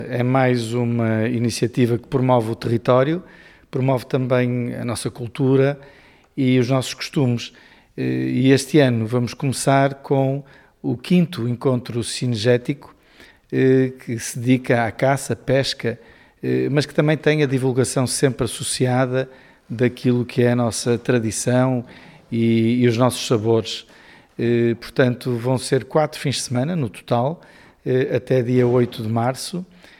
O presidente da Câmara Municipal de Vila Flor, Pedro Lima, sublinha que o evento pretende valorizar o território, a cultura e os produtos locais: